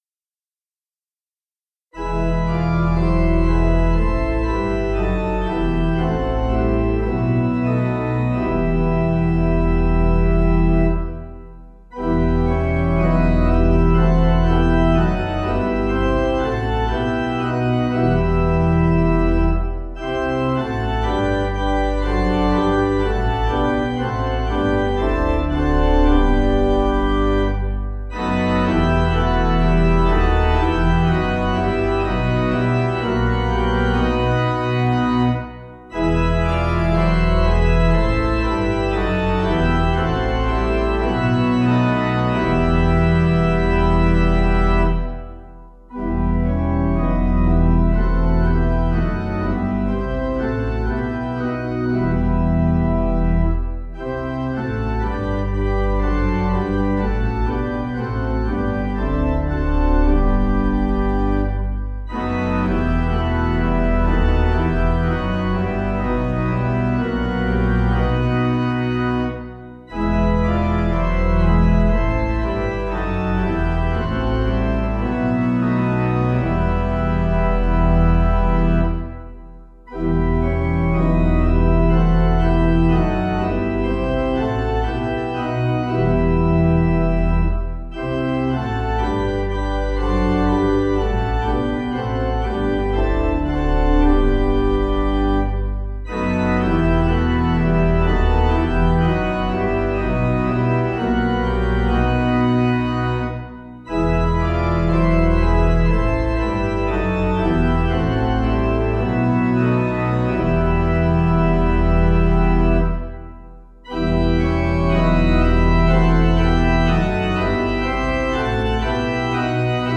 Organ
(CM)   4/Em